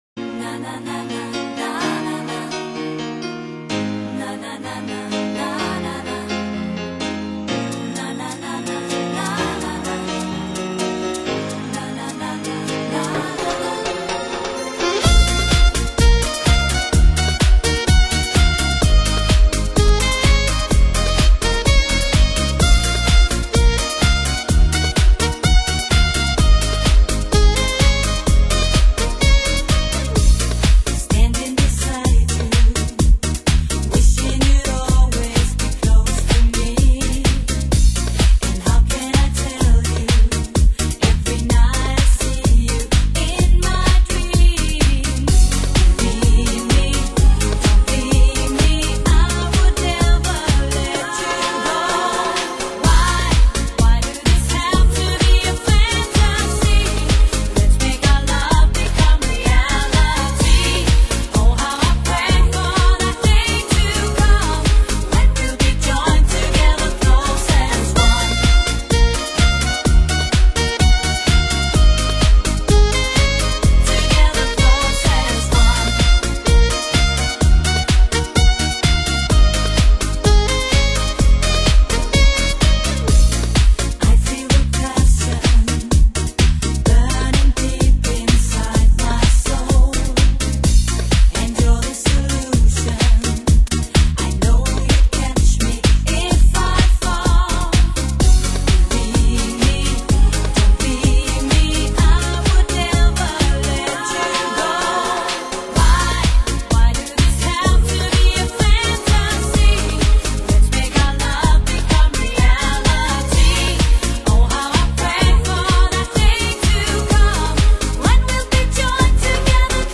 录音技术： 黑胶CD